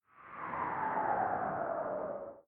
magic_crumple2.ogg